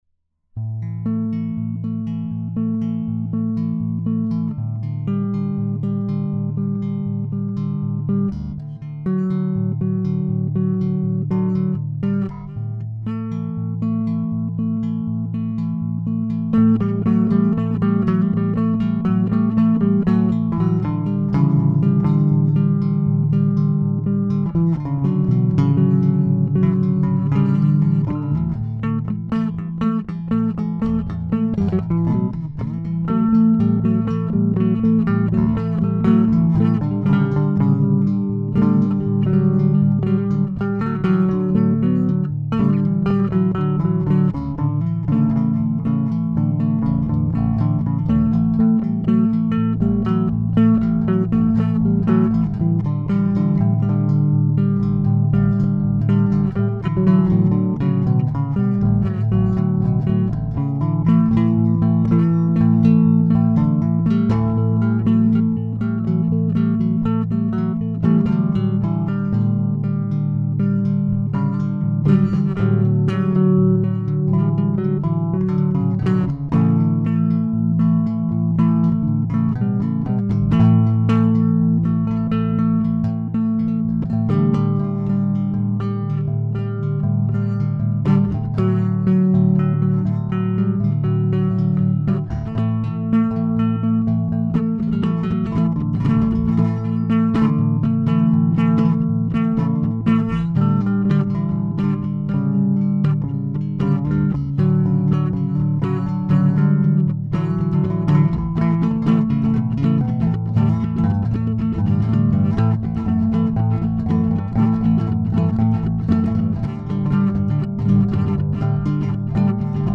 Brightstrings (bass instrumental - 24/11/2016).
There are three tracks on this recording: a chord sequence and two melody/improvisation parts - all played on the same Rickenbacker 4001, all up at the 12th fret.
Some of the melody parts don't quite work, but it's not bad for an "off-the-cuff" take.